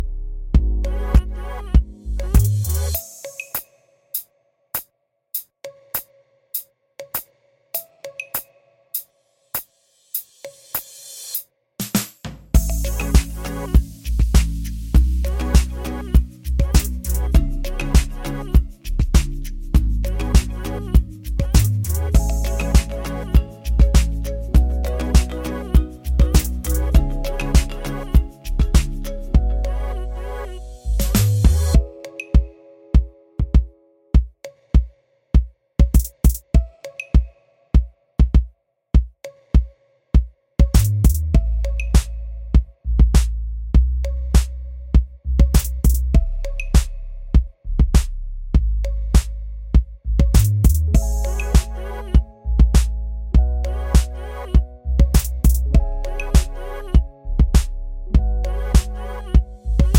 Duets